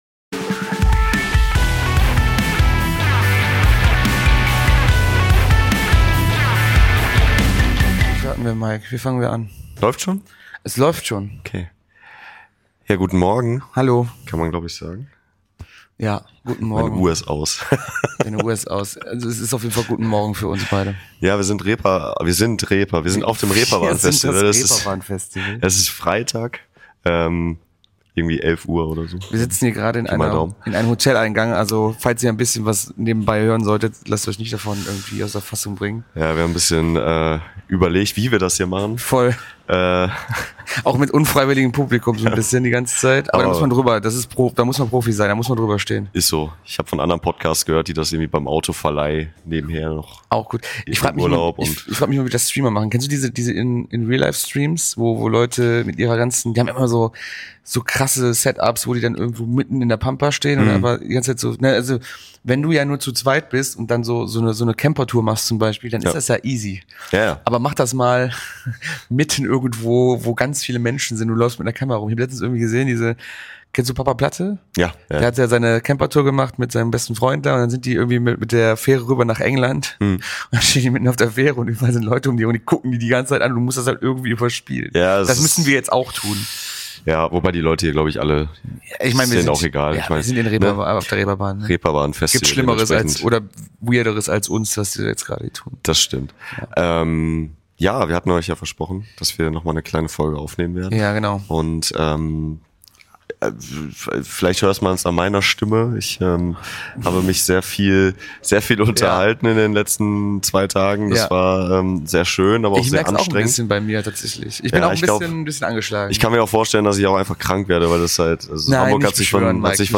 Beschreibung vor 6 Monaten Wir haben uns unter die wichtigen Leute bei einem der coolsten Musikbranchentreffen in Deutschland gemischt. Wer sind die spannendsten Newcomer?